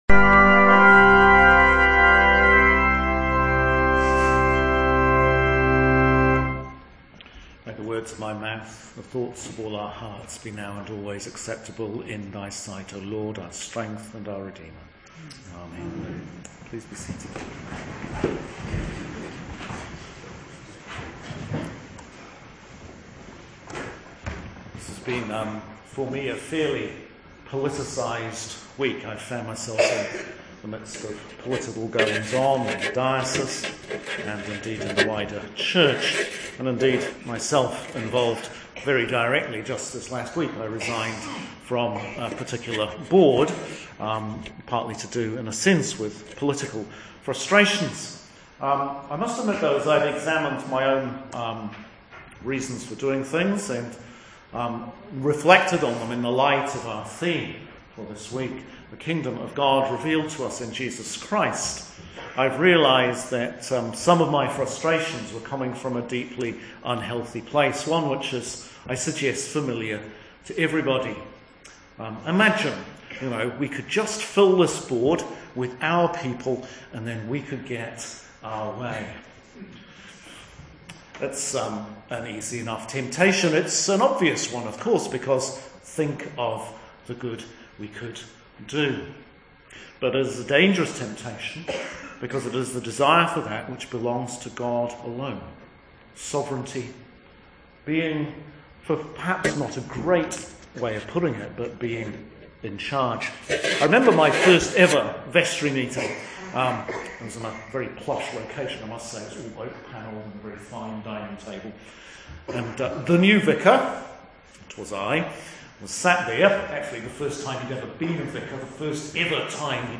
Sermon for Christ the King - 2015 - Year B Daniel 7:9-10, 13-14 Revelation 1:4b-8 John 18:33-37 ‘Thus says the LORD, the King of Israel and her redeemer, the Almighty I am the first ad the last, beside me there is no god’ Isaiah Chapter 44 vs 6 Just this last week I resigned from…